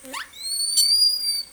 Animal Sounds
Guenon 554